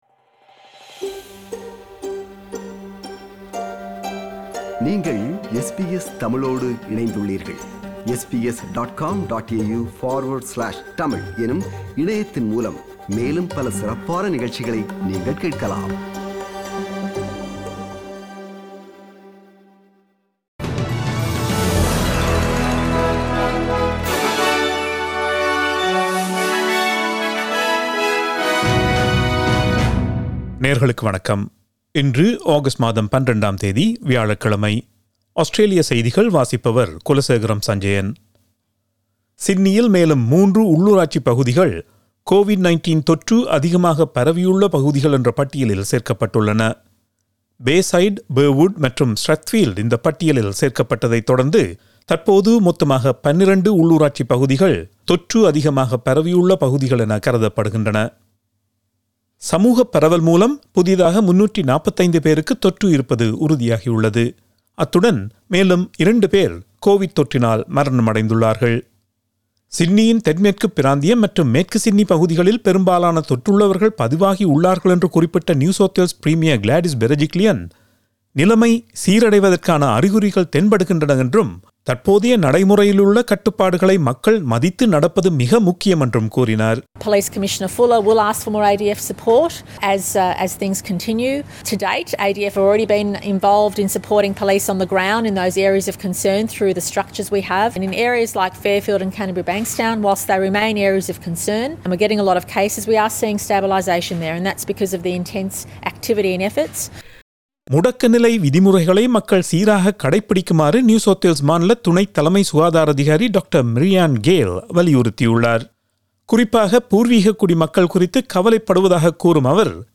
Australian news bulletin for Thursday 12 August 2021.